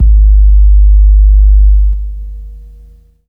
808longkick.wav